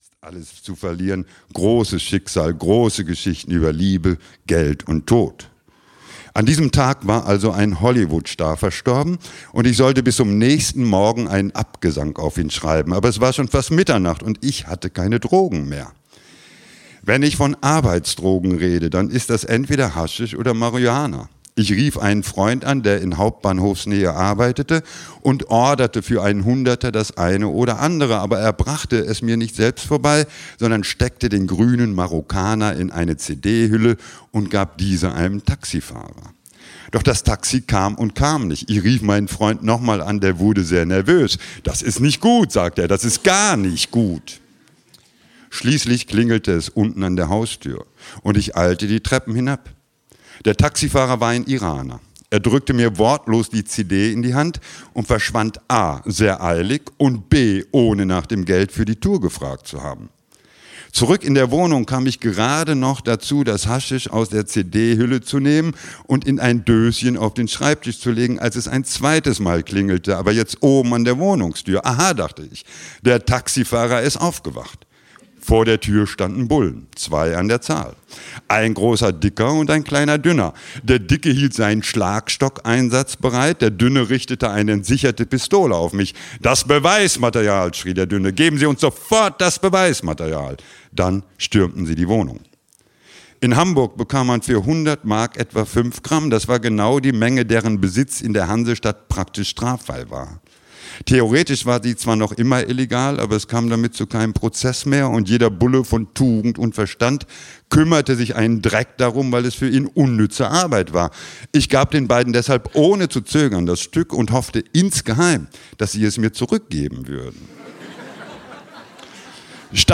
Die Märchentante, der Sultan, mein Harem und ich (Live-Lesung) - Helge Timmerberg - Hörbuch